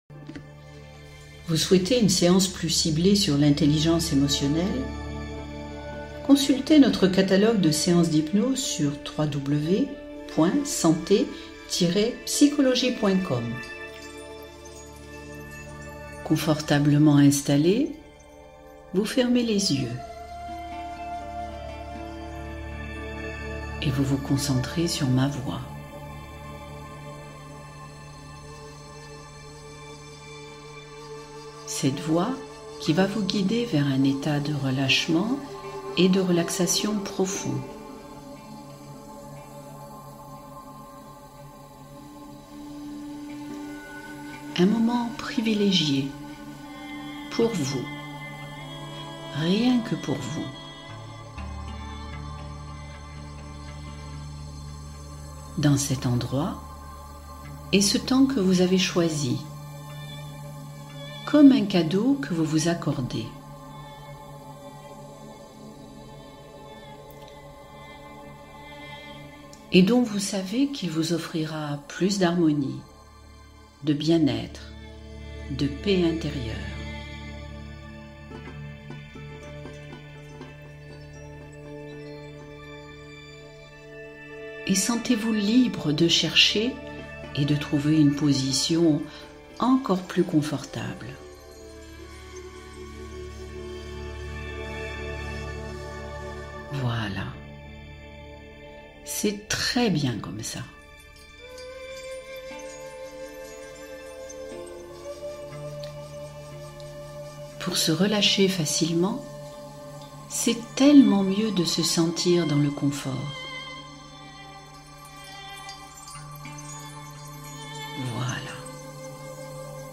Hypnose : libérer les pensées négatives et la lourdeur mentale